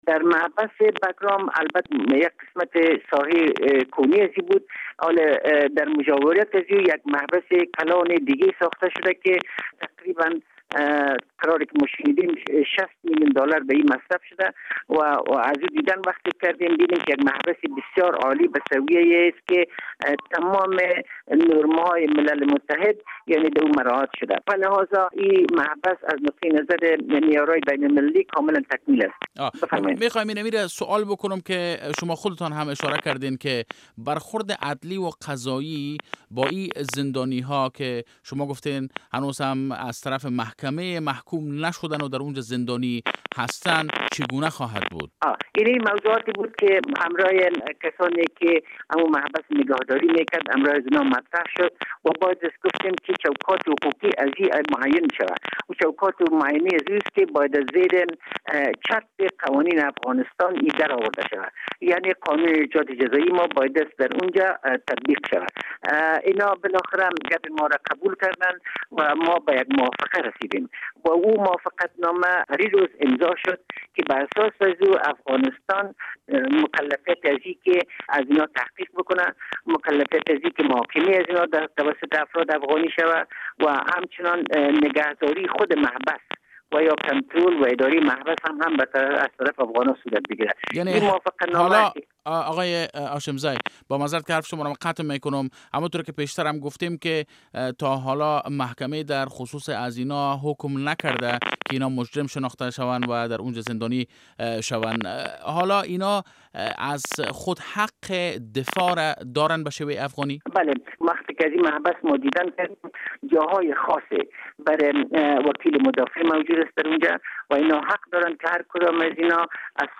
مصاحبه با سید قاسم هاشم زی معین وزارت عدلیه د ر مورد تسلیم دهی مسوولیت زندان بگرام به افغانستان